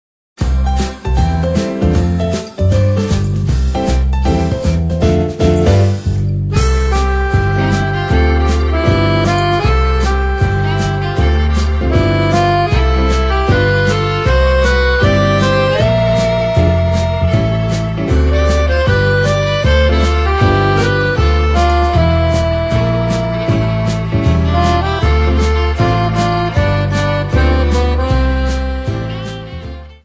Accordion Music 3 CD Set.